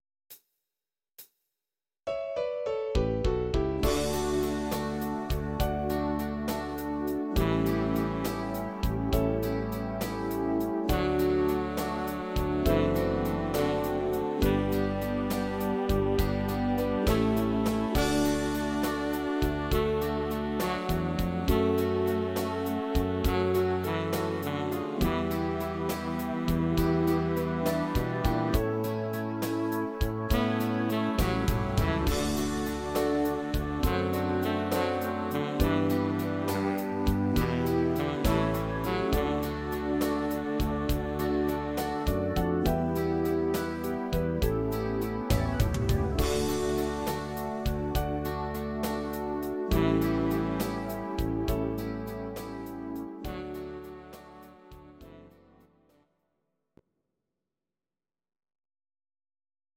These are MP3 versions of our MIDI file catalogue.
Please note: no vocals and no karaoke included.
Your-Mix: Oldies (2910)